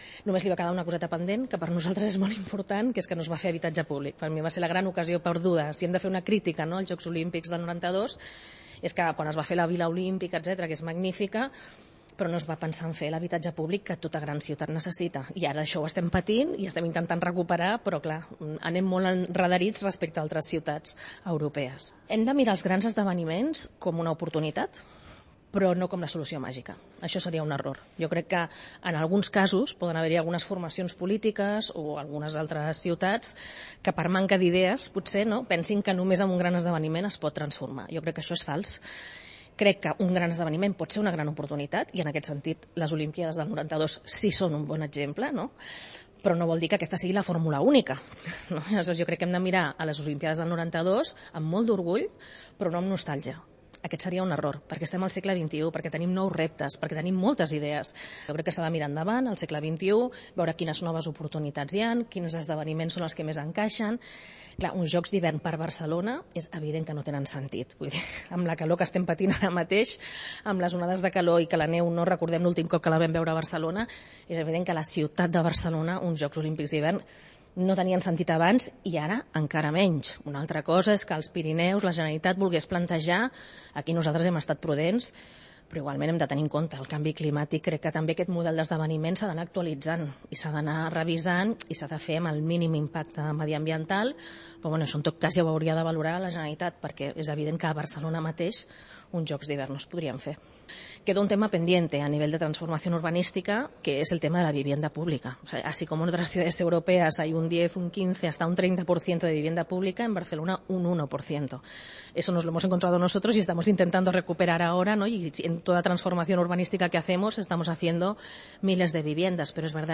Lo ha dicho en una entrevista de Europa Press con motivo de los 30 años de los Juegos, inaugurados el 25 de julio, que la capital catalana acogió en el 92 y que el Ayuntamiento conmemorará este jueves con un acto institucional en el Saló de Cent del consistorio.